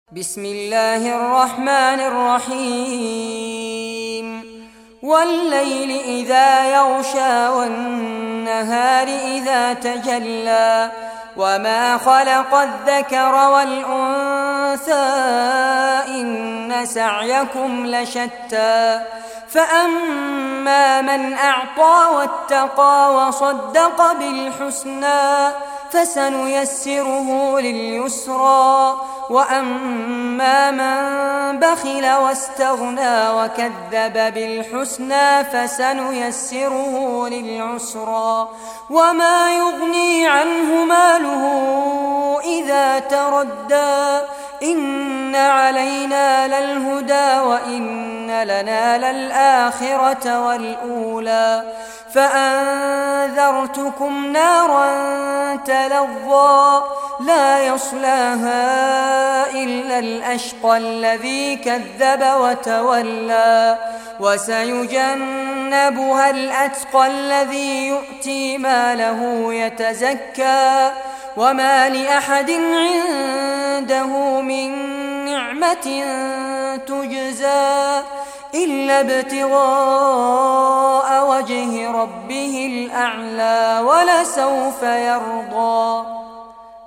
Surah Lail Recitation by Sheikh Fares Abbad
Surah Lail, listen or play online mp3 tilawat / recitation in Arabic in the beautiful voice of Sheikh Fares Abbad.